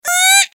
دانلود صدای پرنده 30 از ساعد نیوز با لینک مستقیم و کیفیت بالا
جلوه های صوتی